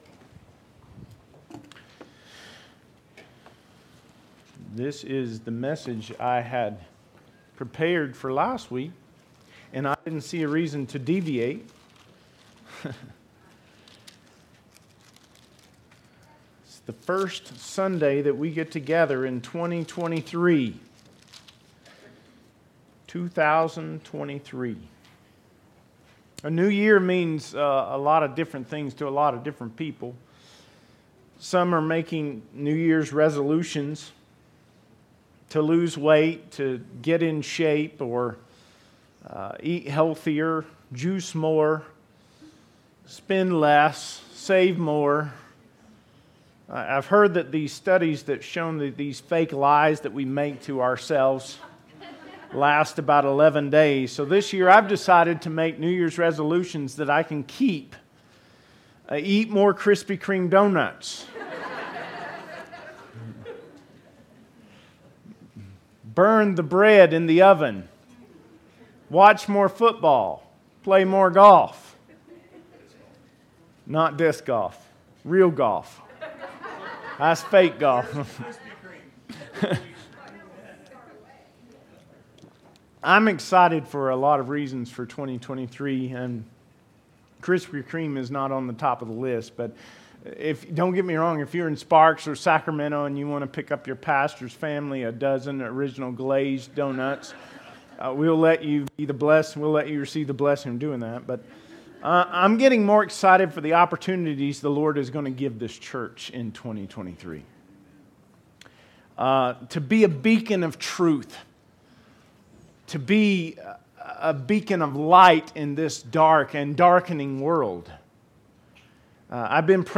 2023 Sermons - Smith Valley Baptist Church